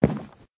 sounds / step / wood3.ogg
wood3.ogg